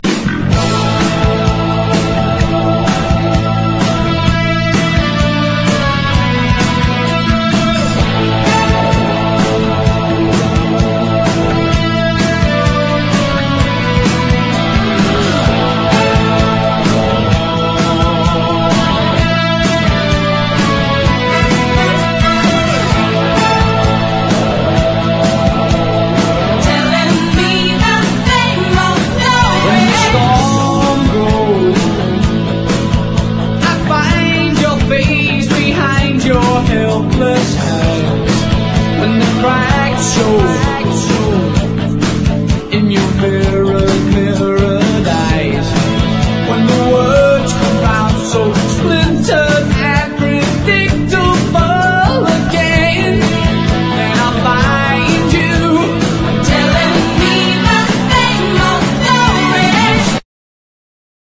ROCK / 80'S/NEW WAVE. / NEW WAVE / OLD SCHOOL